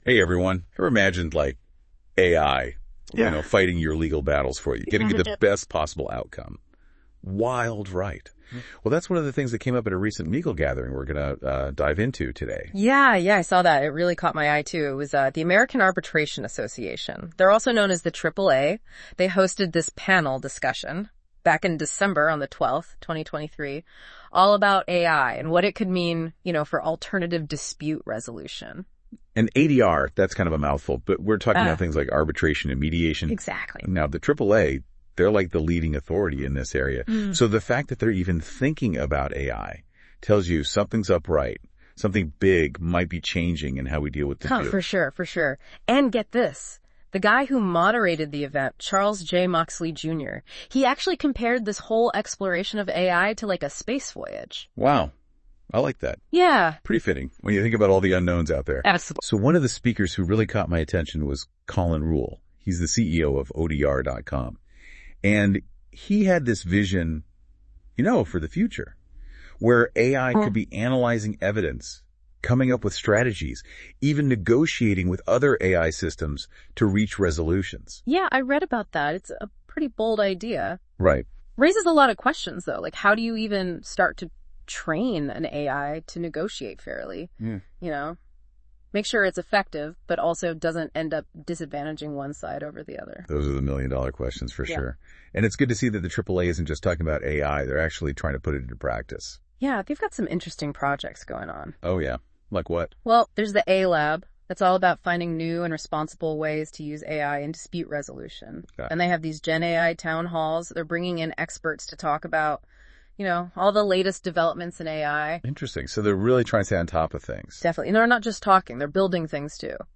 View the full webinar on Vimeo